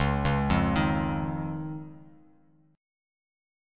C#add9 chord